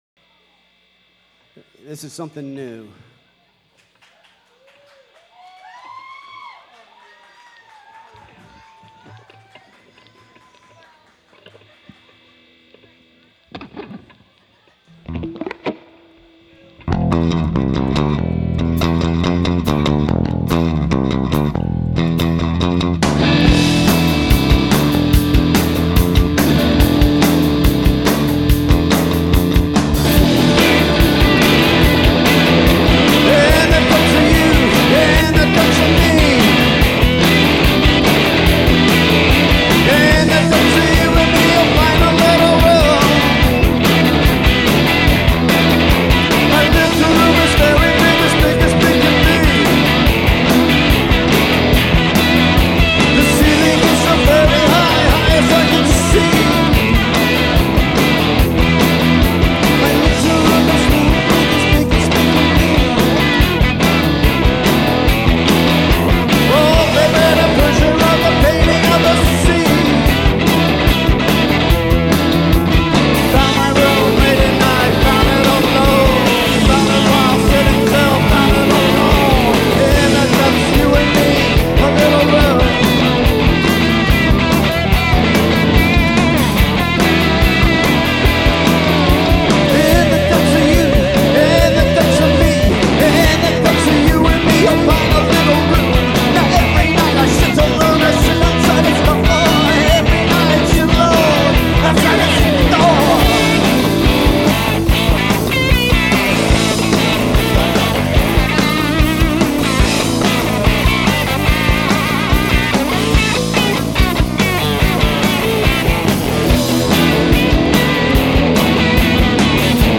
reunion show from 2006
bass
guitar
drums